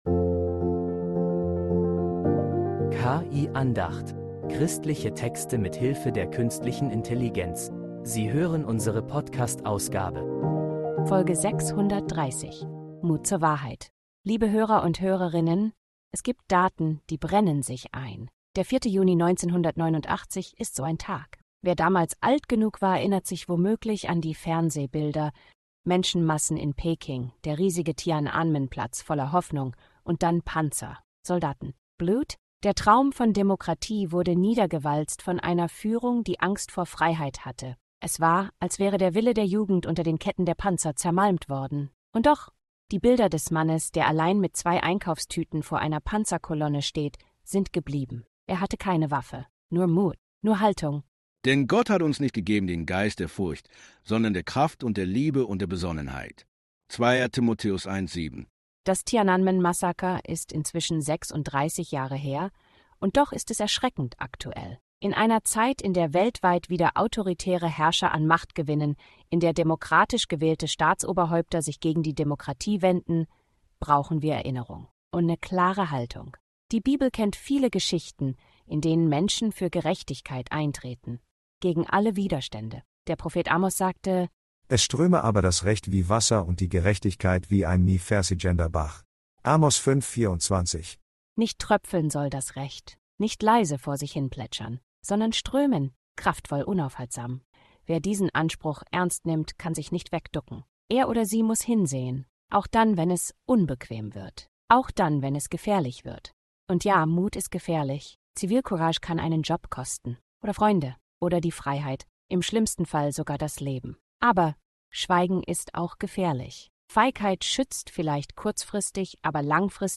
Eine Andacht über Mut, Wahrheit und Hoffnung.